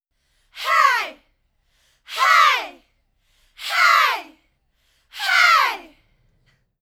Track 11 - Vocal Hey 04.wav